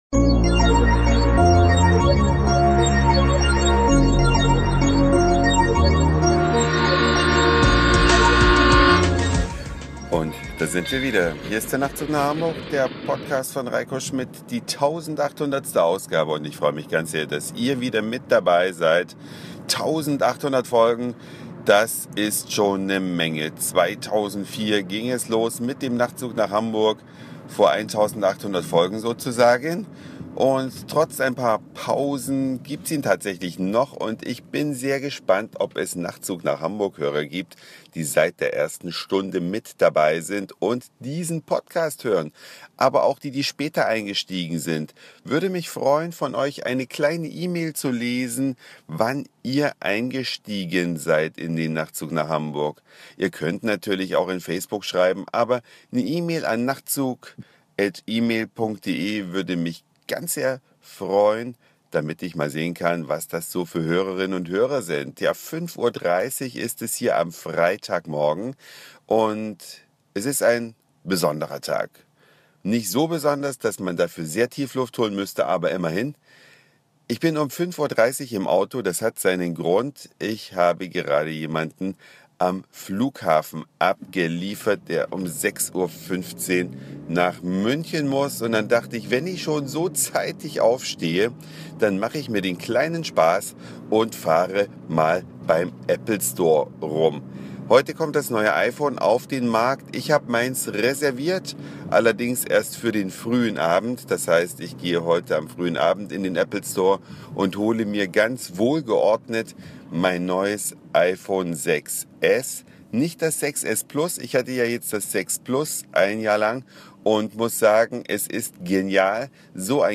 Eine Autofahrt durch Hamburg ab 5:30 Sehenswürdigkeiten und Schlangestehen